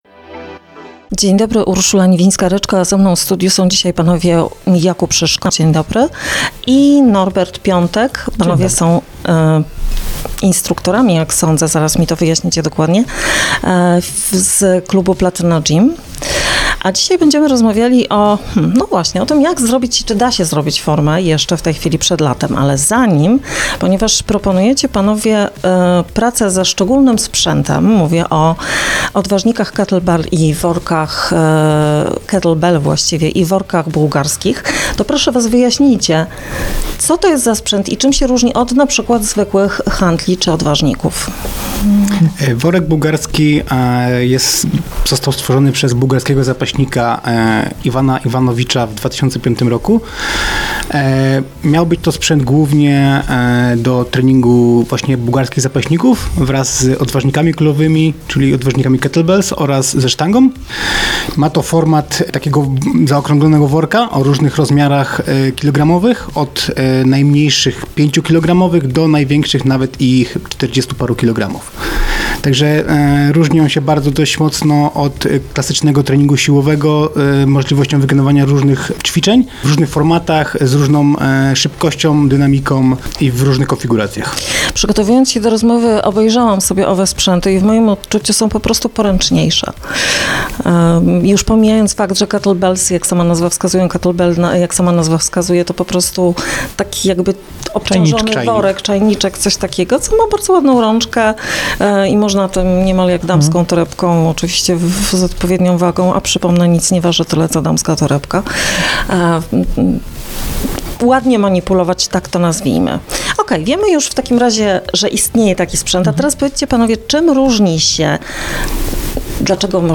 Rozmowa dostępna jest również na facebookowym profilu Radia Radom: